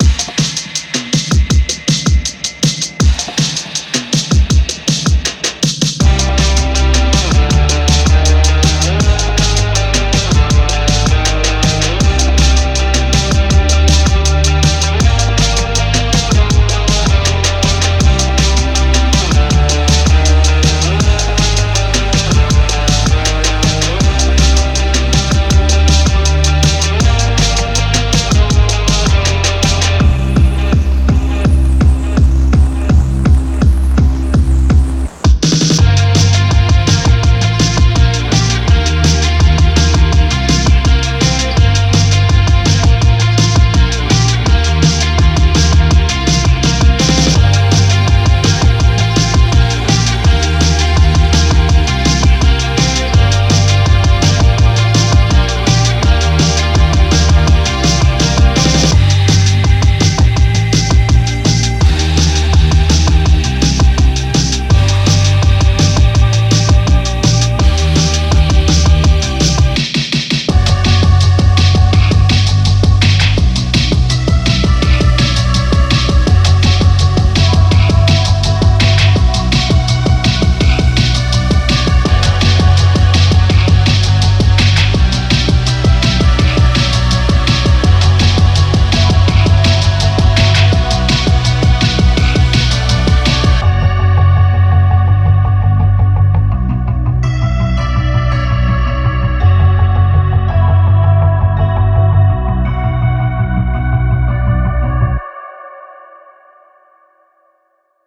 タイトなグルーヴ、冷たい質感、目的をもって打ち出すループ。
どのサウンドも、無駄な装飾に頼ることなく、重み、粗さ、感情を備えています。
デモサウンドはコチラ↓
Genre:Rock